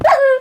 wolf_hurt3.ogg